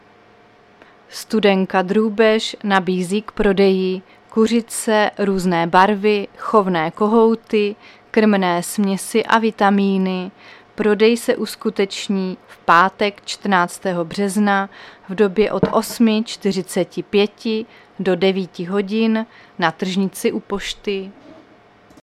Záznam hlášení místního rozhlasu 12.3.2025
Zařazení: Rozhlas